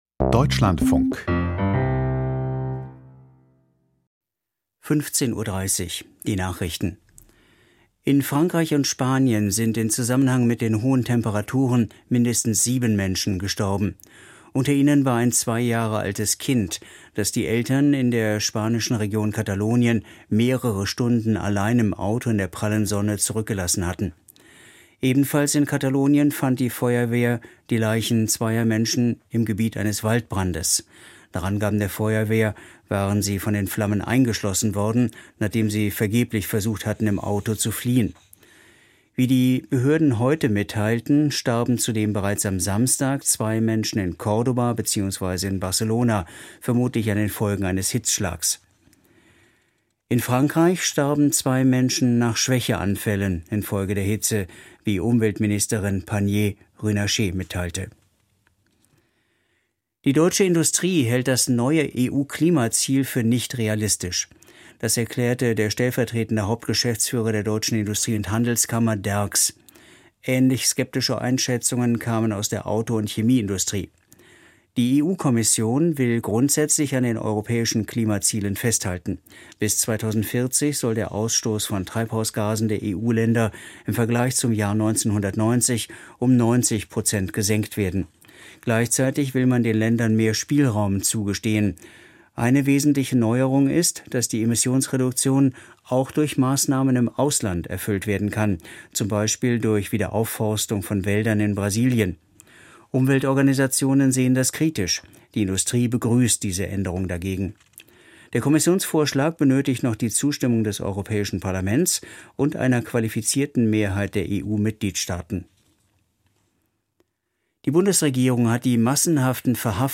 Die Nachrichten vom 02.07.2025, 15:30 Uhr
Aus der Deutschlandfunk-Nachrichtenredaktion.